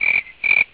crickets.wav